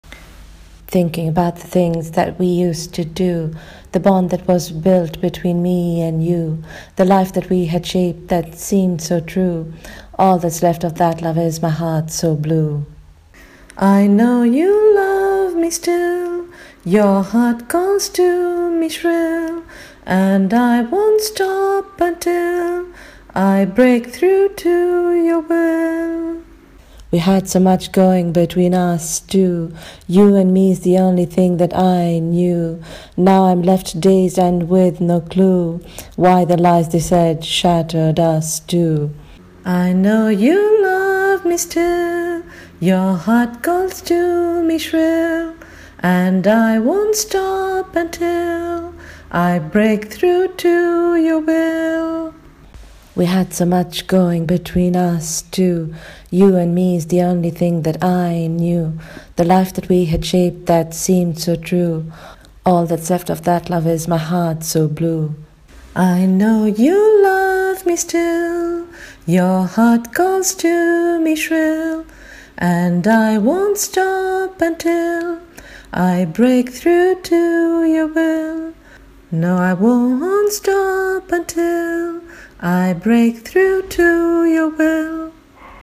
Singing of the lyrics: